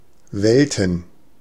Ääntäminen
Ääntäminen Tuntematon aksentti: IPA: [ˈvɛltn̩] IPA: [ˈvɛltən] Haettu sana löytyi näillä lähdekielillä: saksa Käännöksiä ei löytynyt valitulle kohdekielelle. Welten on sanan Welt monikko.